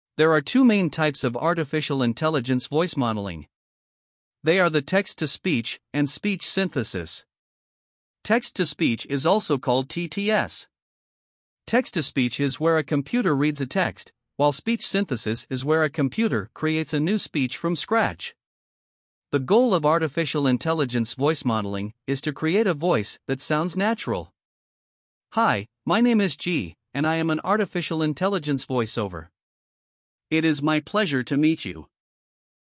voiceover
Voiceover - Male